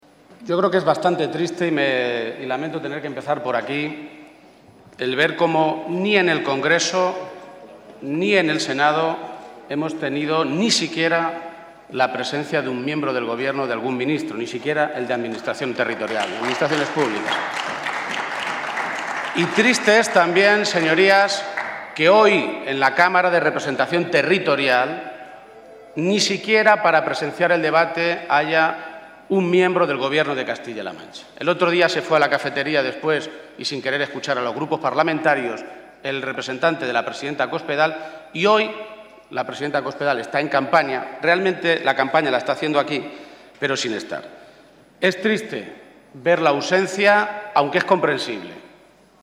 Emiliano García-Page durante su intervención en el Senado
Audio Page-primera intervención Senado 1